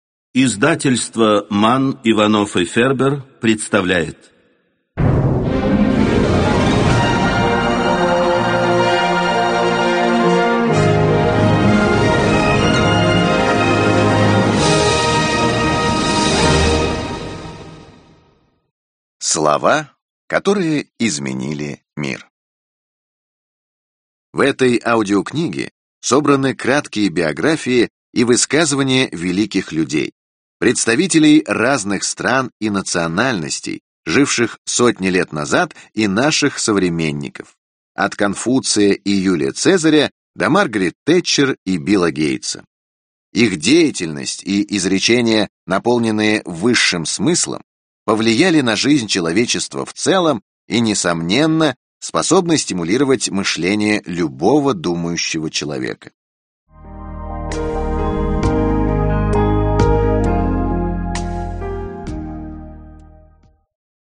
Аудиокнига Слова, которые изменили мир | Библиотека аудиокниг